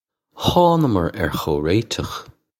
Hawng-gummar air khoe-ray-chukh
This is an approximate phonetic pronunciation of the phrase.